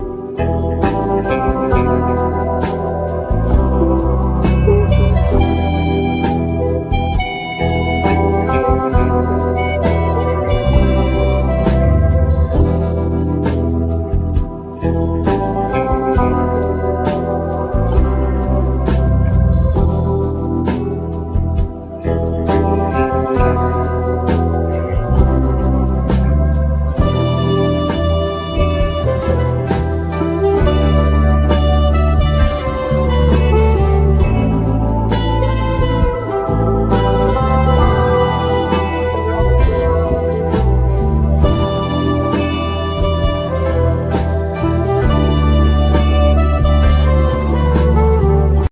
Trumpet,Flugelhorn
Bass,Keyboards,Guitar,Samples
Hammond organ,Rhodes piano
Drums
Guitar,Pedal steel,Violin